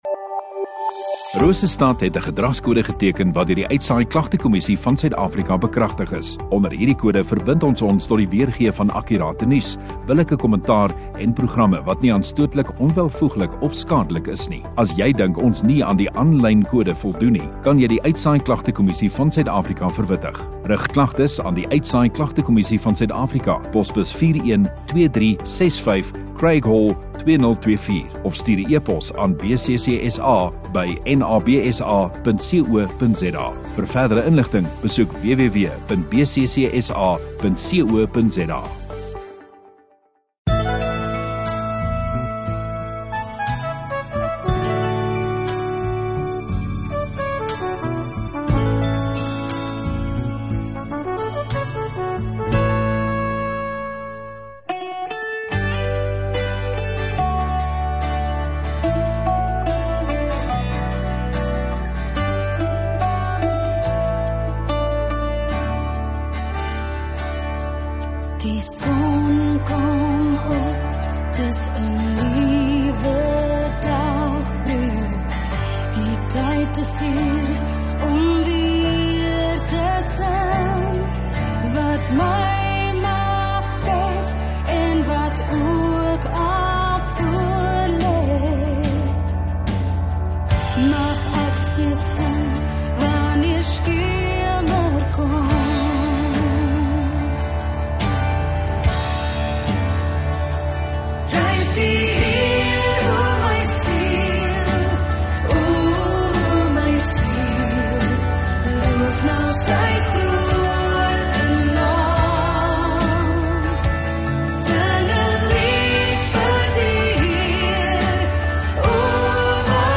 26 May Sondagaand Erediens